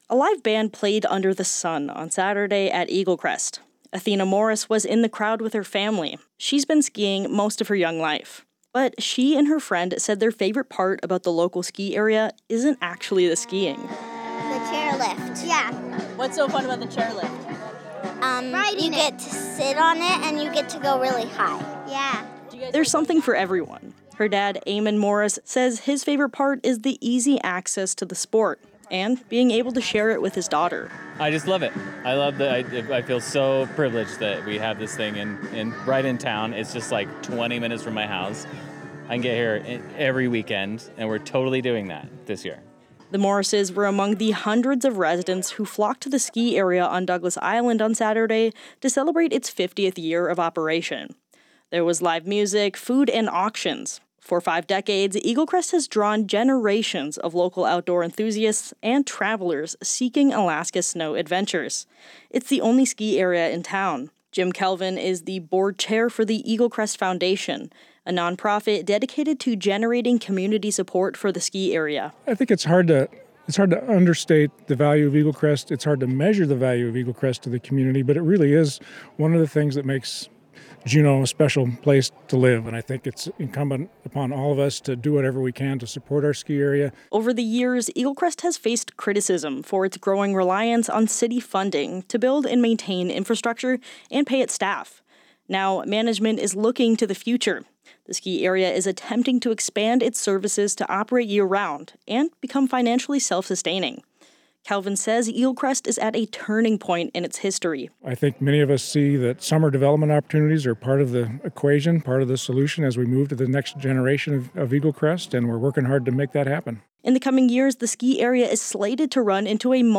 A live band played under the sun outside the lodge.
There was live music, food and auctions.